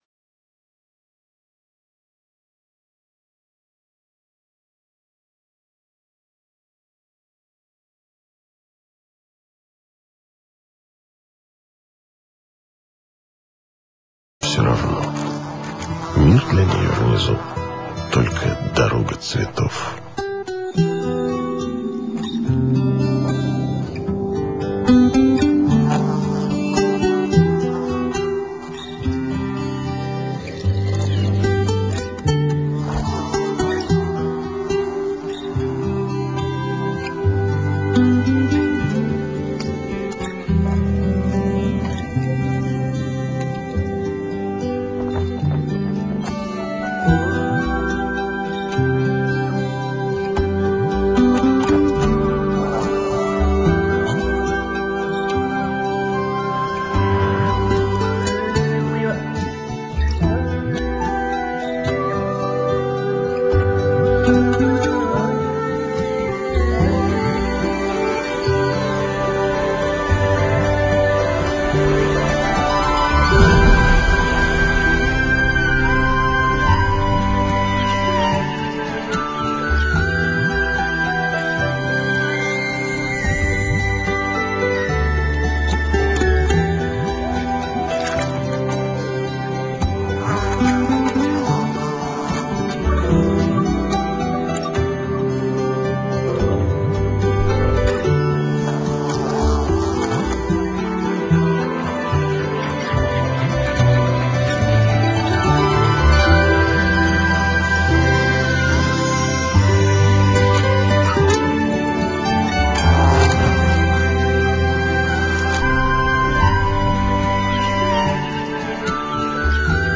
Цей файл подібний до АМ радіо